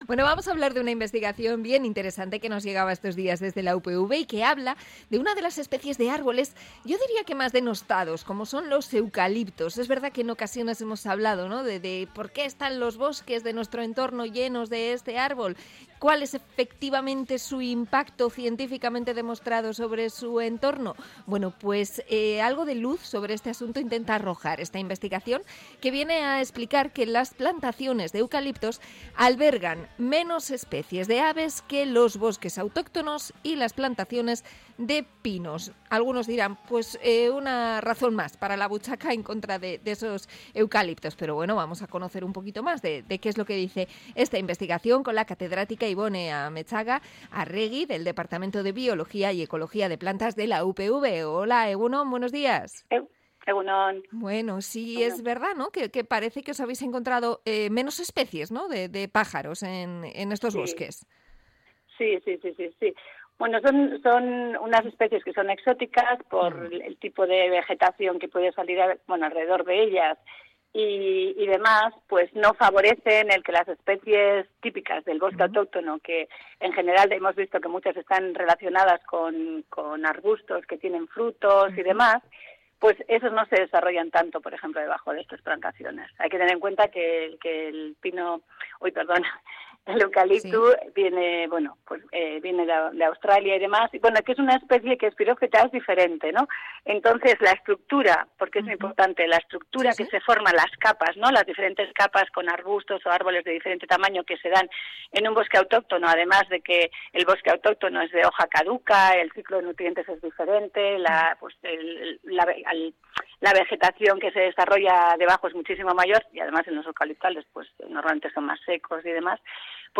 Entrevista a bióloga de EHU sobre los eucaliptos